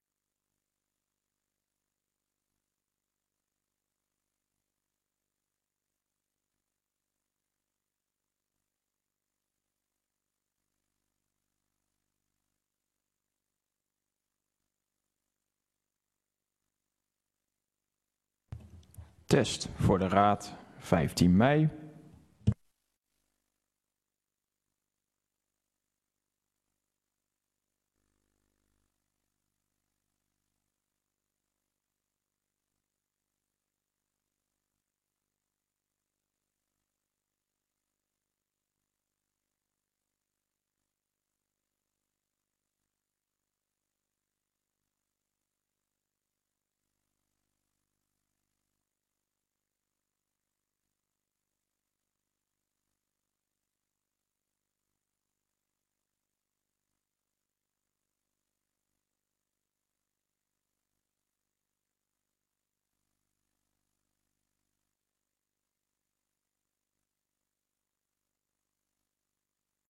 Raadscommissie Fysieke Leefomgeving (FL) 15 mei 2024 20:00:00, Gemeente Oude IJsselstreek
DRU Industriepark - Conferentiezaal